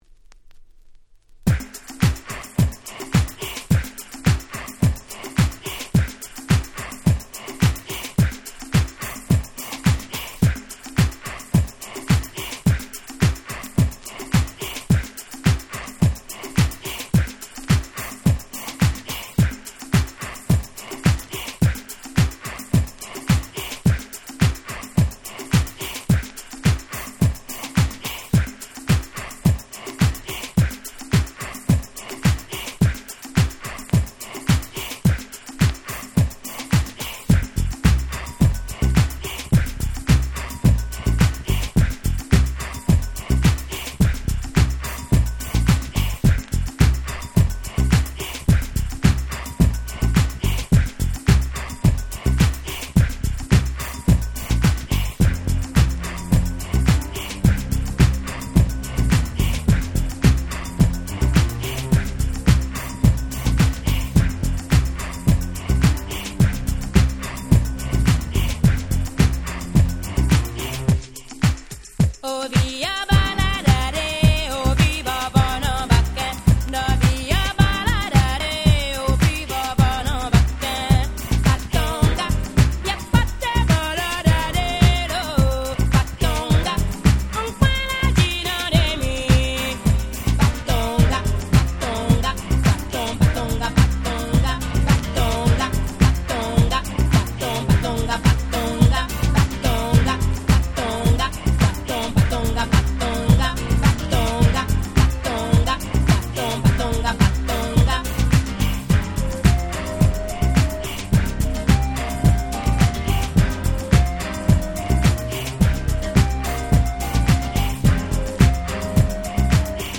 91' Very Nice Afrobeats !!
エキゾチックなメロディーと歌がもう堪りません！！